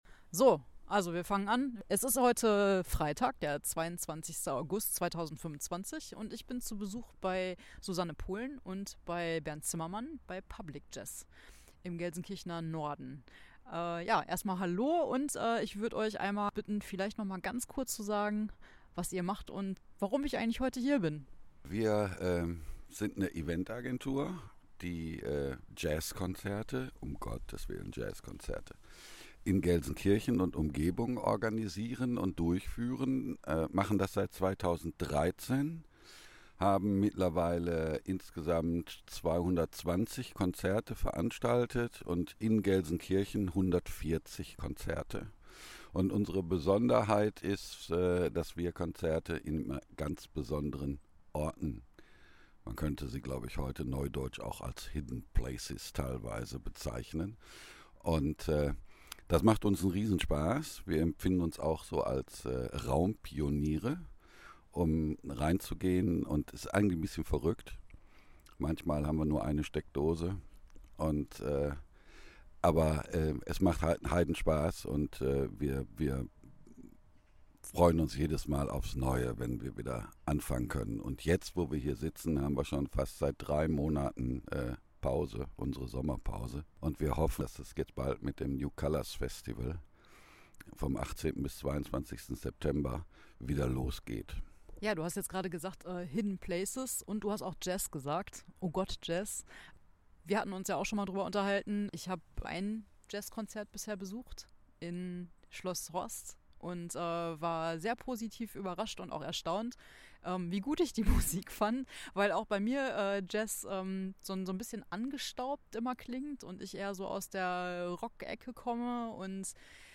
Lasst euch auch anstecken und hört rein, was die beiden mir über das Festival erzählt haben!